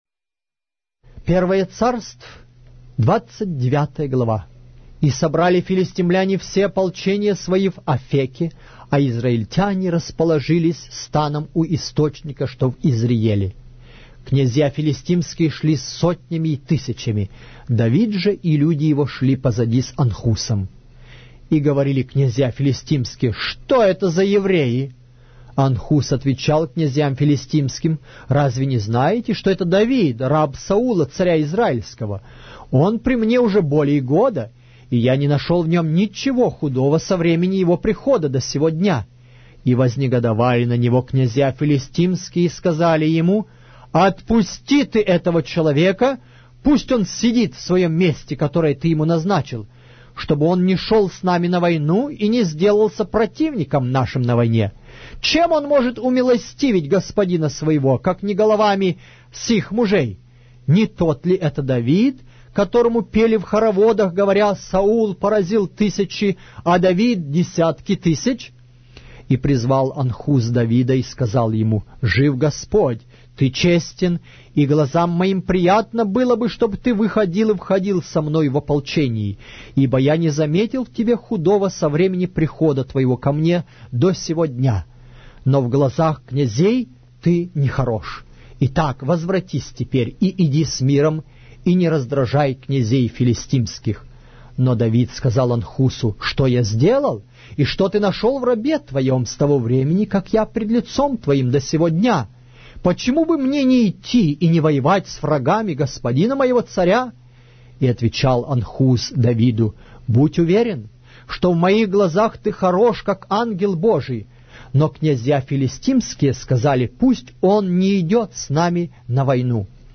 Глава русской Библии с аудио повествования - 1 Samuel, chapter 29 of the Holy Bible in Russian language